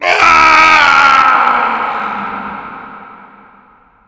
falling.wav